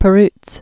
(pə-rts, pĕrəts), Max Ferdinand 1914-2002.